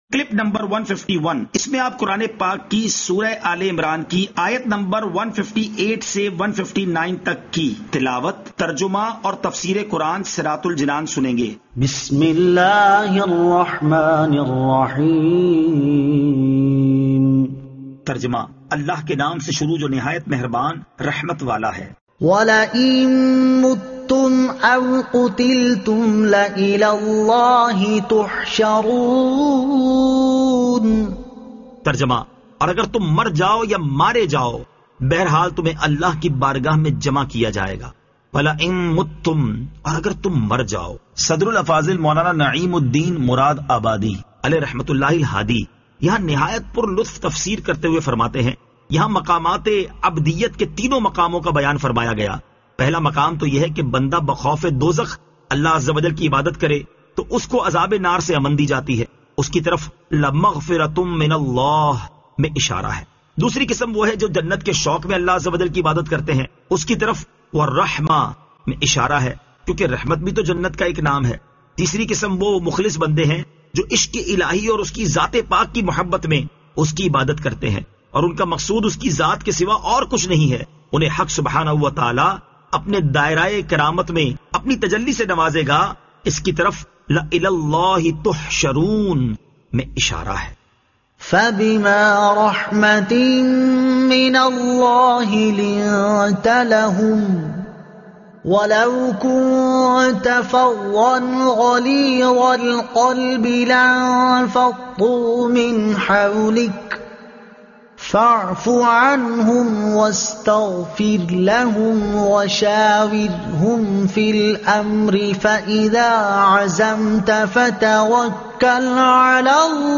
Surah Aal-e-Imran Ayat 158 To 159 Tilawat , Tarjuma , Tafseer